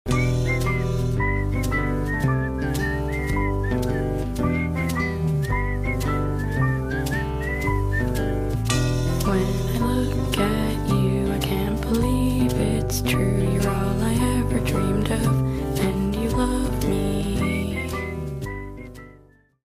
Wahahaha sound effects free download